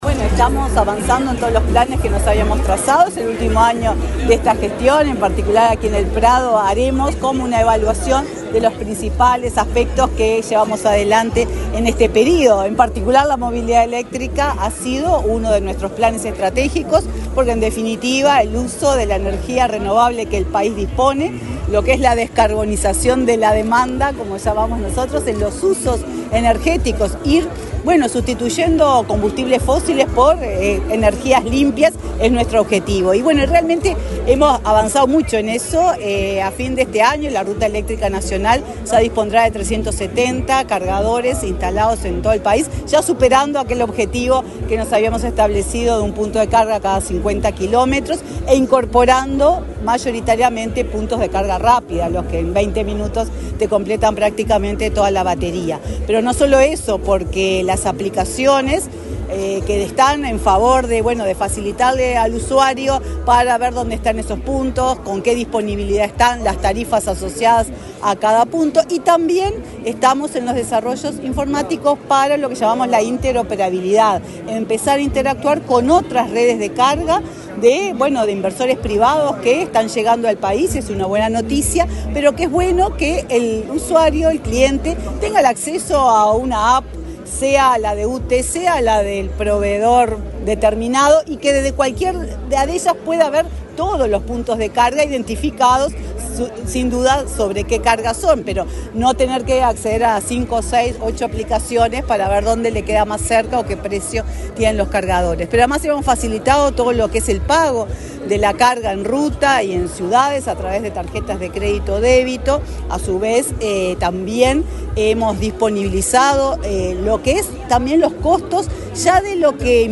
Declaraciones de la presidenta de UTE, Silvia Emaldi
La presidenta de la UTE, Silvia Emaldi, dialogó con la prensa, antes de inaugurar el stand del ente en la Expo Prado 2024.